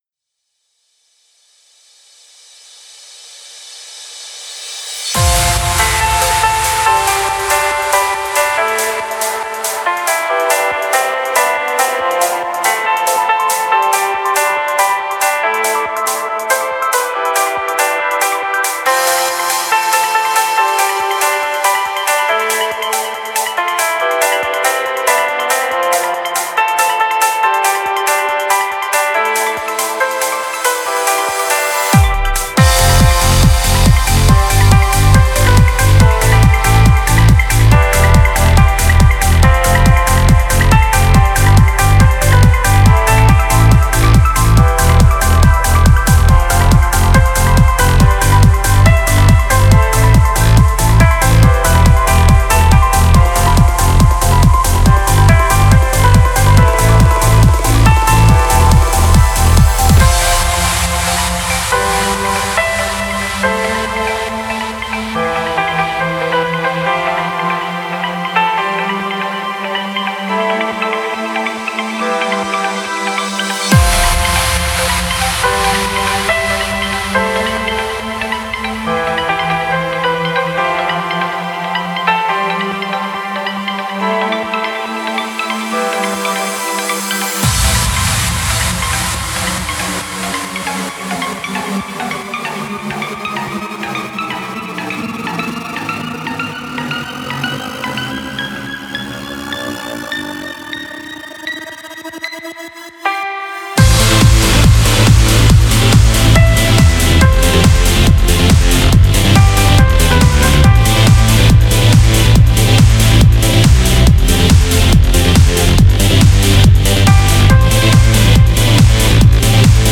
歌入れ予定のトランス・インストバージョン・デモ
制作中のトランスです。歌を入れる予定の楽曲ですが、デモバージョンではピアノでメロディを入れています。